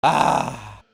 Meme Sound Effect for Soundboard